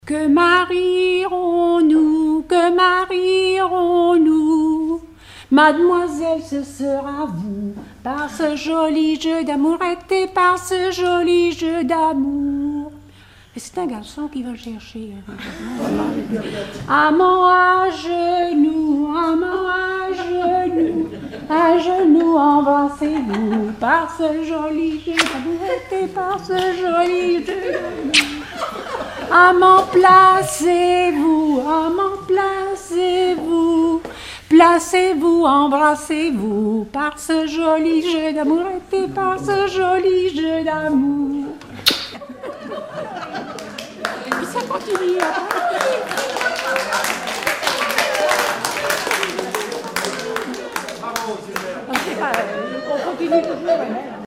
Rondes enfantines à baisers ou mariages
danse : ronde à marier
Regroupement de chanteurs du canton
Pièce musicale inédite